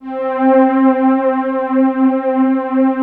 70 STRINGS-R.wav